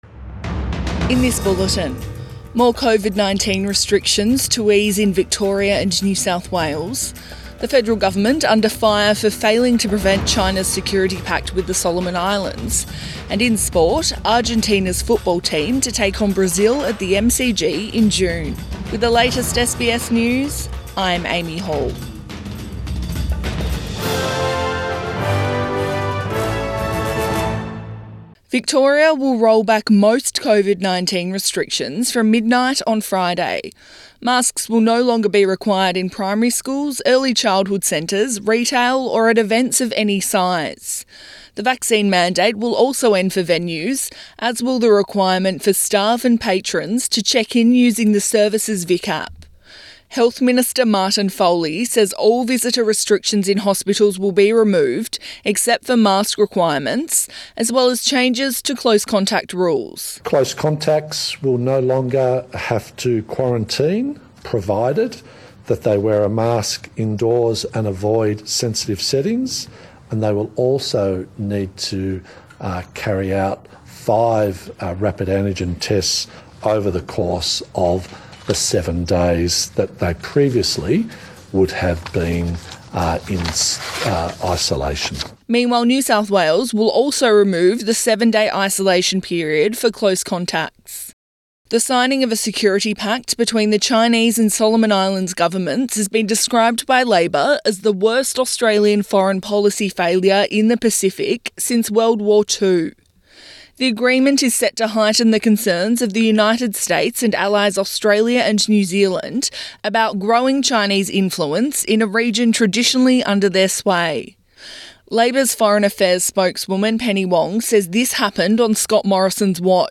Midday bulletin 20 April 2022